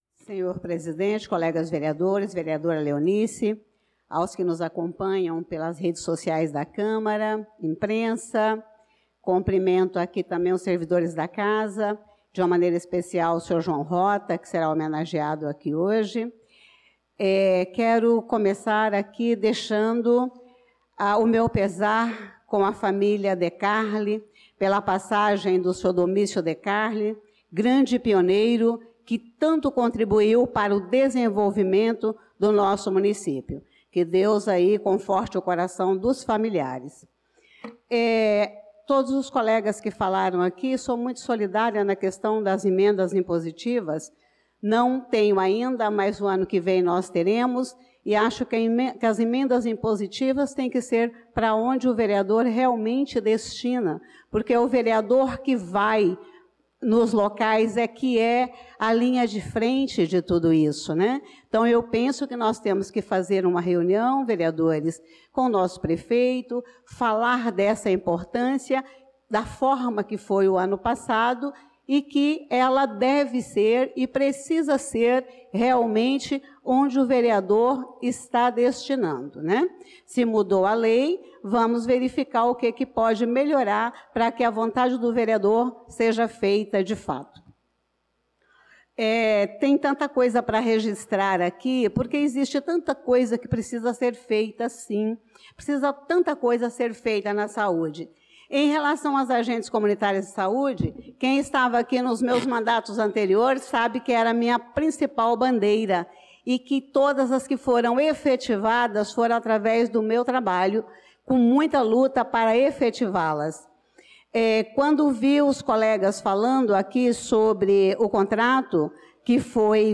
Pronunciamento da vereadora Elisa Gomes na Sessão Ordinária do dia 05/05/2025